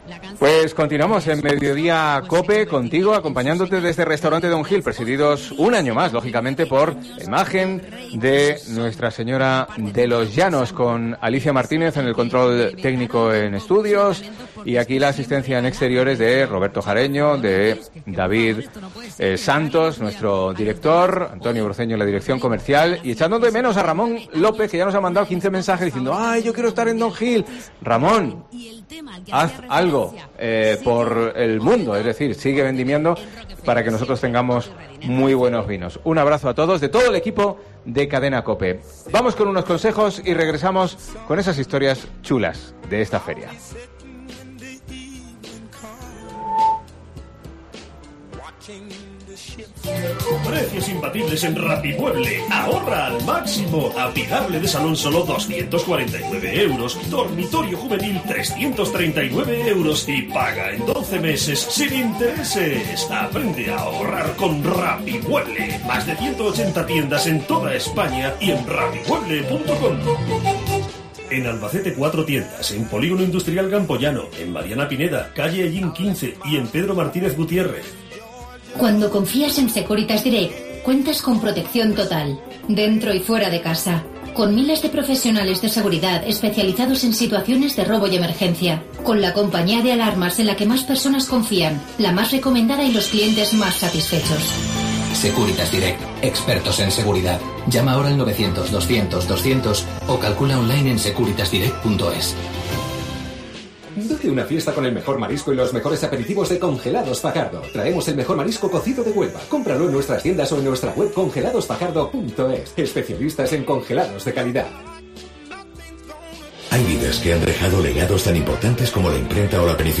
Mediodía COPE Albacete especial desde Don Gil